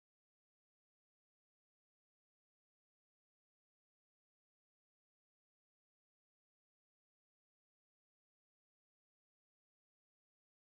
Kindertänze: Hausstand des Bauern
Tonart: F-Dur
Taktart: 2/4
Tonumfang: große None
Besetzung: vokal